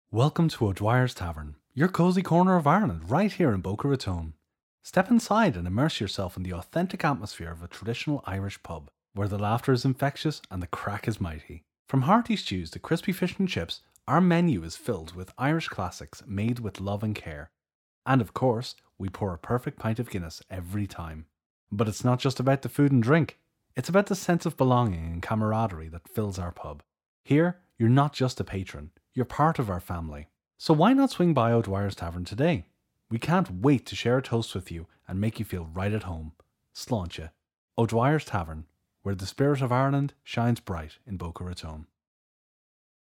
Every file is recorded to broadcast quality and delivered ready to use — no fuss.
Pub Radio Ad Demo